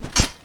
melee-hit-3.ogg